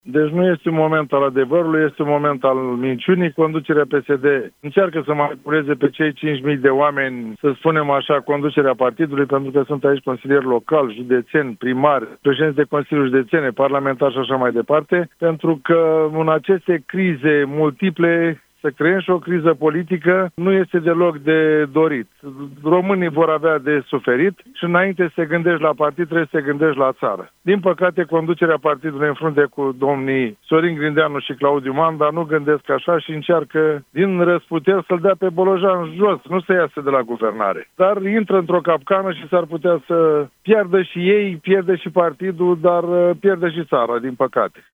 Conducerea partidului încearcă să-i manipuleze pe cei 5 mii de membri care vor participa la vot, spune în exclusivitate la Europa FM, primarul PSD din Buzău, Constantin Toma.